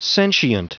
Prononciation du mot sentient en anglais (fichier audio)
Prononciation du mot : sentient